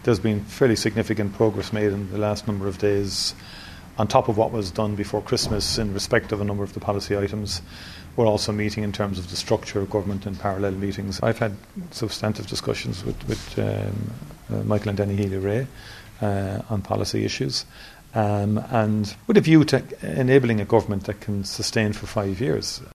Tanaiste Michael Martin says a number of ‘substantial’ meetings have already taken place this week with potential coalition partners: